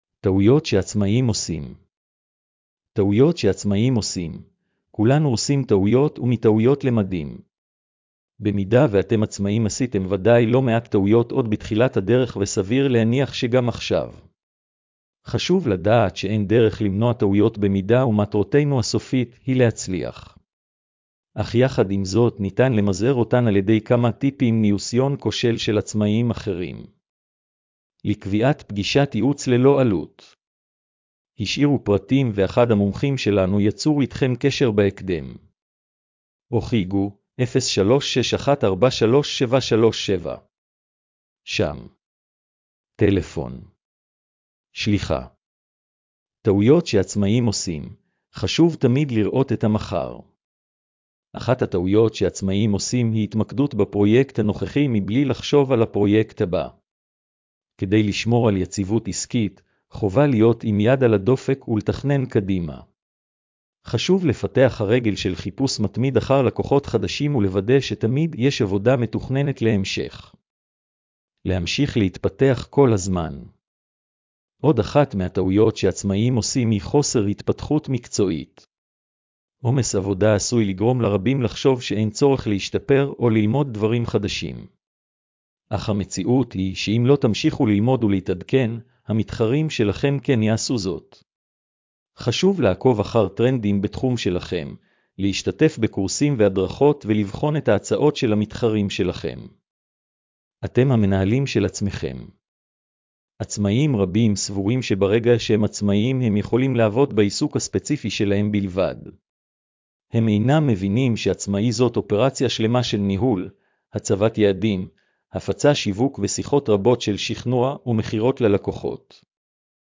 הקראת המאמר לבעלי מוגבלות: